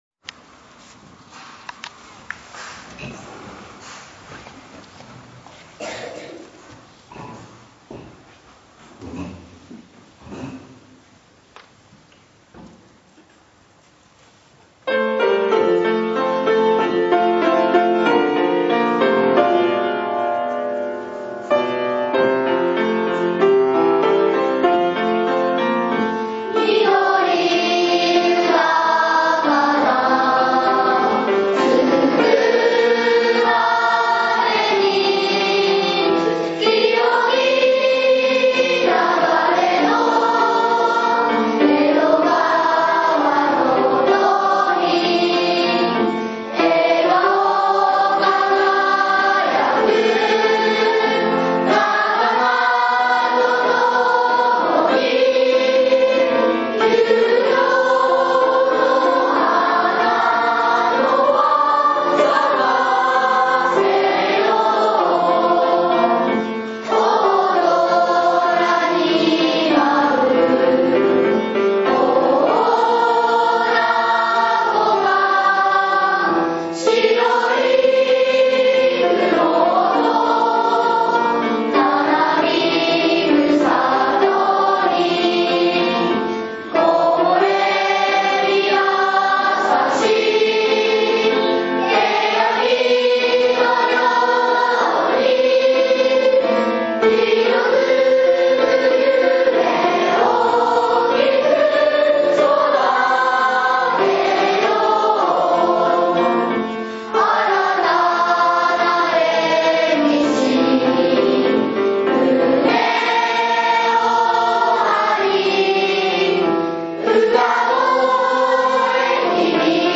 １学期終業式